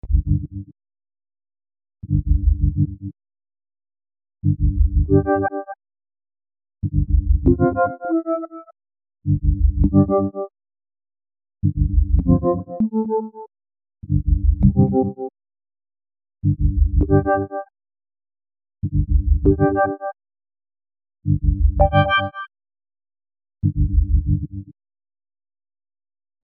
Sound per Zufallsgenerator
Diese drei Sounds hat Icarus ohne weiteres Zutun gewürfelt: